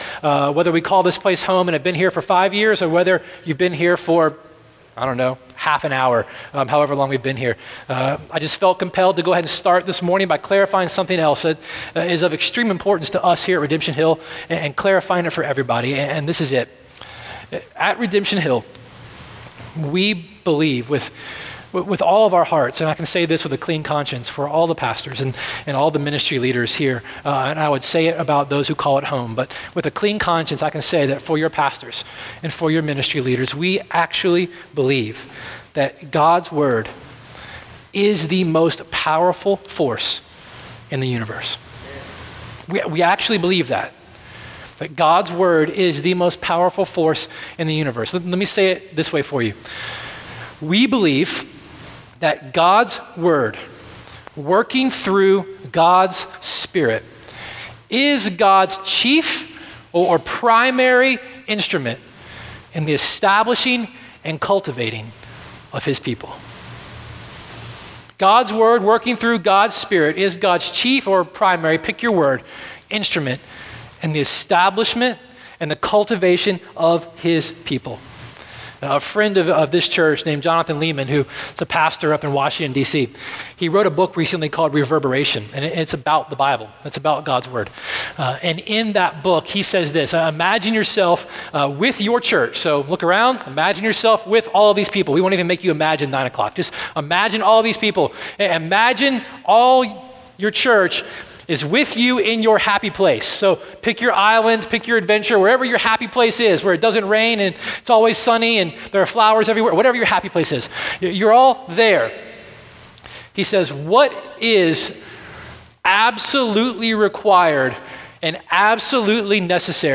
Connection Card Give Online Prayer Request This sermon on The Drama of Redemption